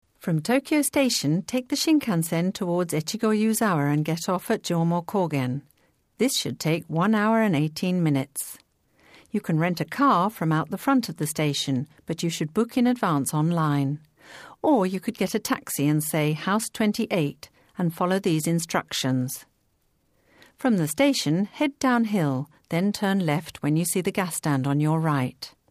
イギリス英語、ブリティッシュアクセントの英語ナレーターを手配いたします。